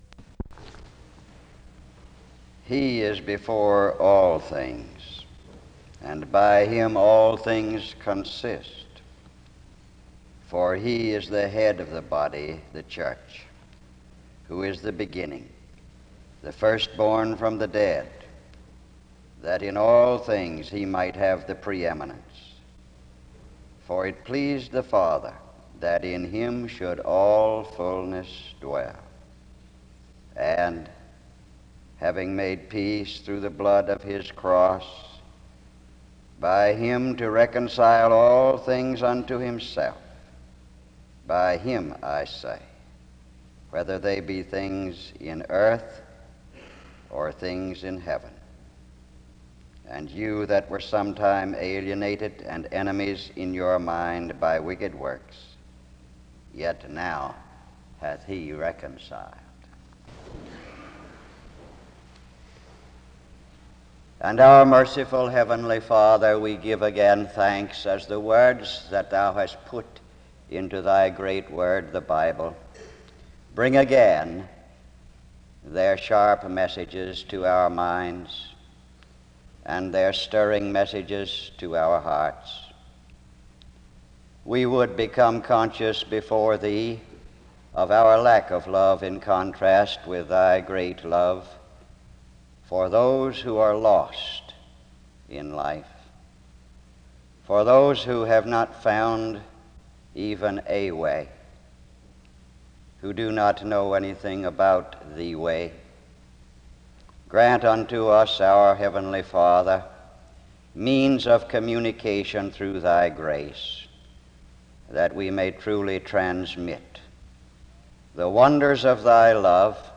[The original audio fades out before the message finishes.]
SEBTS Chapel and Special Event Recordings SEBTS Chapel and Special Event Recordings